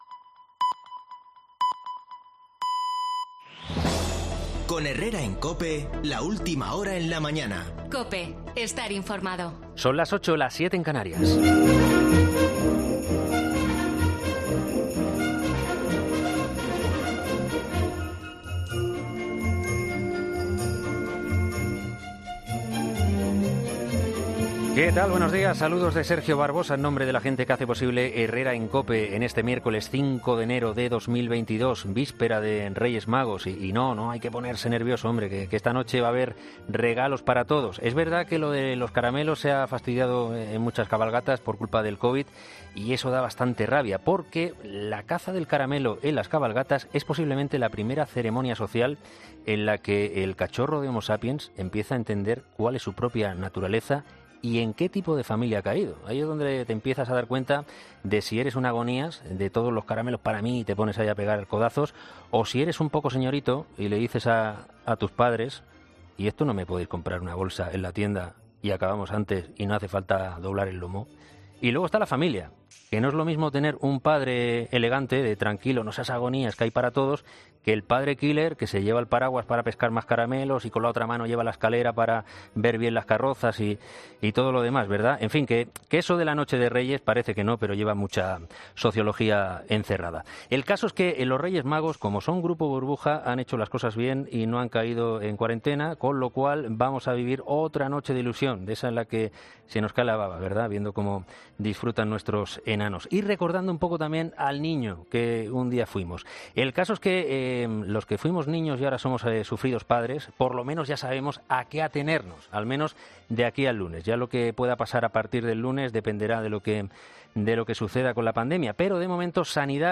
[ESCUCHA LA EDITORIAL DEL MIÉRCOLES 5 DE ENERO DE "HERRERA EN COPE"]